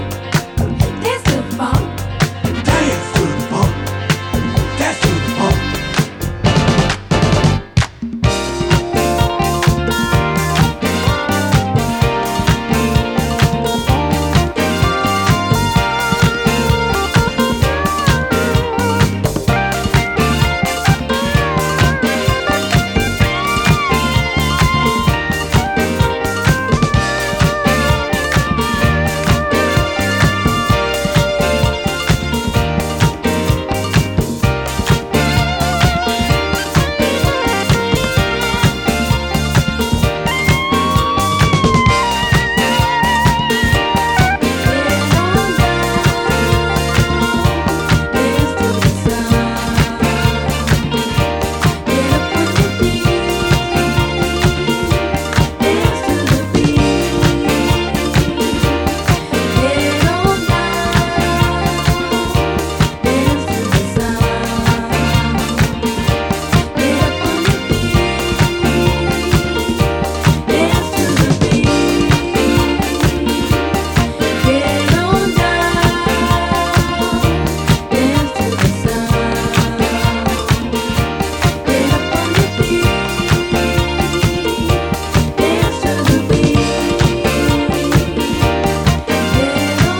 タイトでグルーヴィーなブギー・チューンから美しいハーモニーを聴かせるミッド〜スローまで充実の内容！